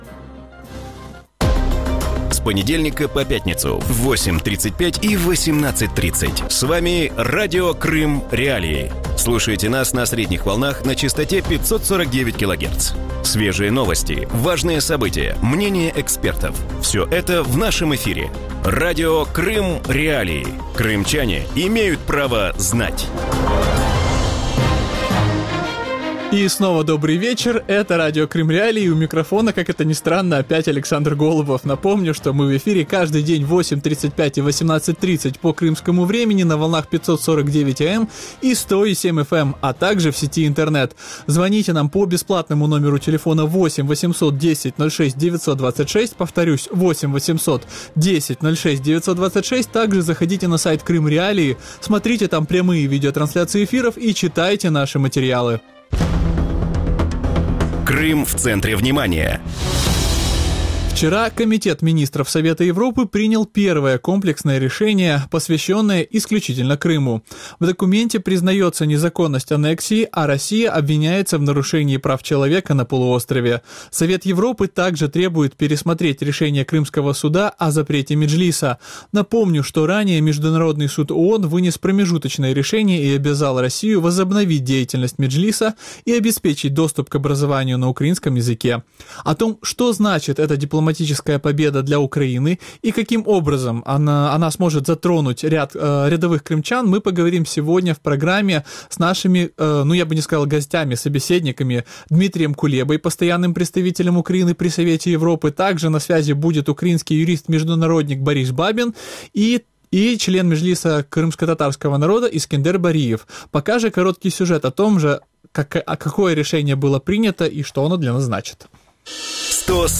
В вечернем эфире Радио Крым.Реалии обсуждают первое решение Комитета министров Совета Европы по Крыму. Чего требует Совет Европы от России, аннексировавшей Крым? Как украинская дипломатия решает крымский вопрос?